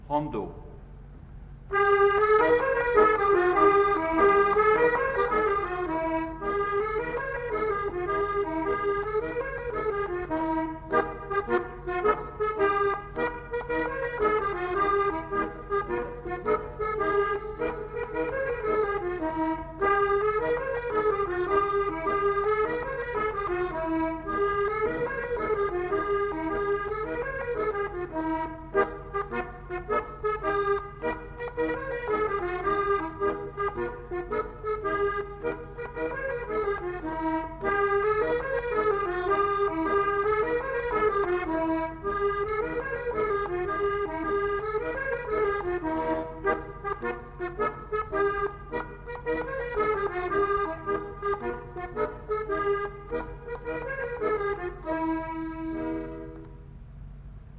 enquêtes sonores
Rondeau